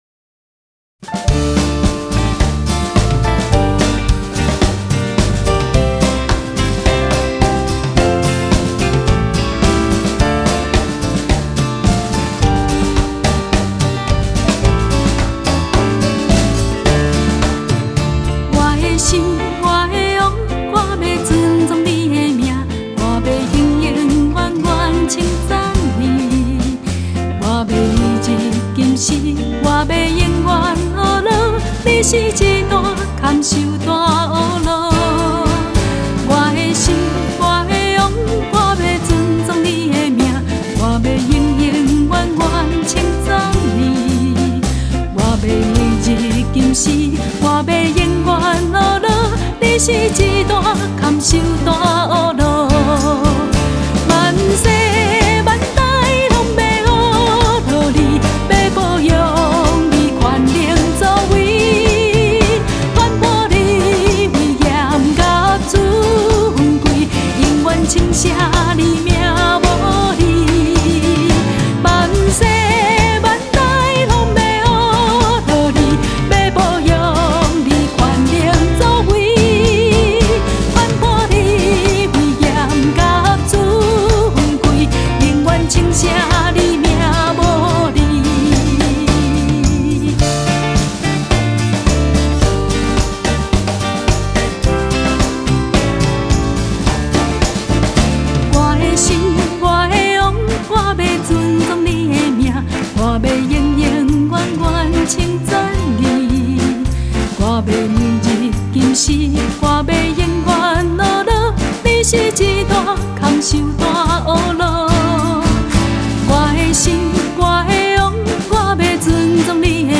前奏 → 主歌 → 副歌 → 主歌 → 副歌 → 副歌(放慢)